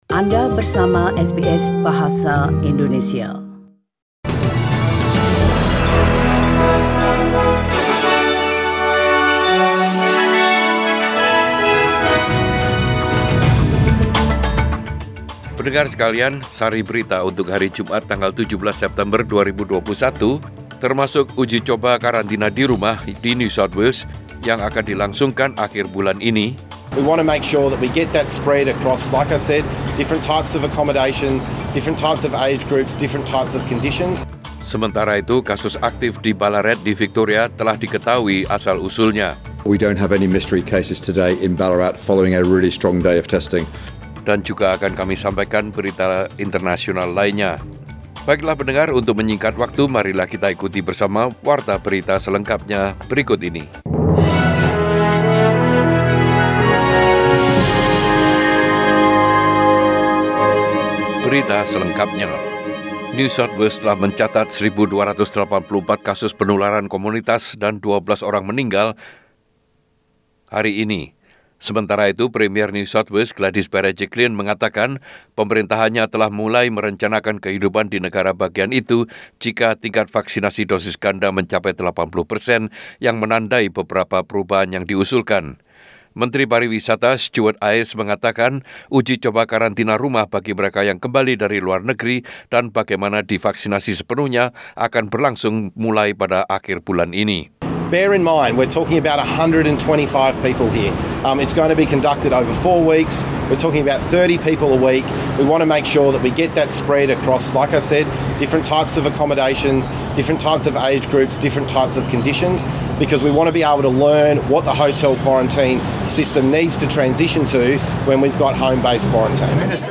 SBS Radio News in Bahasa Indonesia - 17 September 2021
Warta Berita SBS Radio dalam Bahasa Indonesia Source: SBS